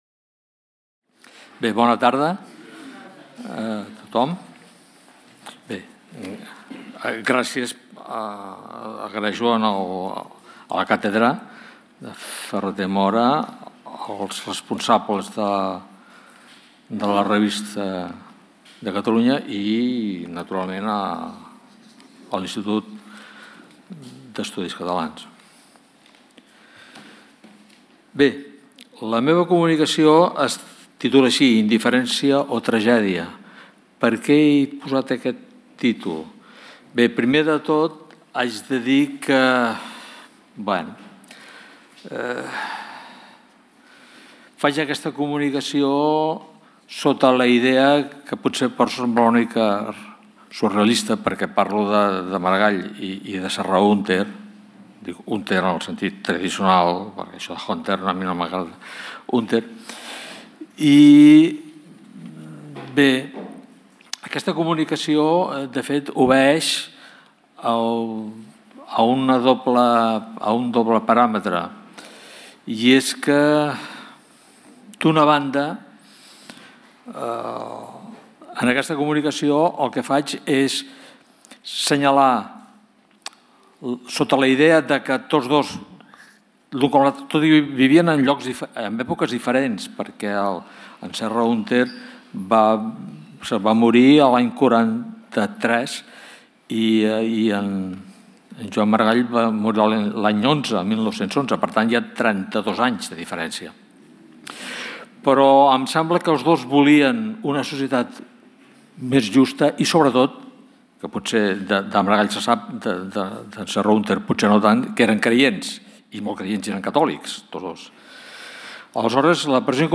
en el marc del Simposi Trias 2024 sobre el centenari de la Revista de Catalunya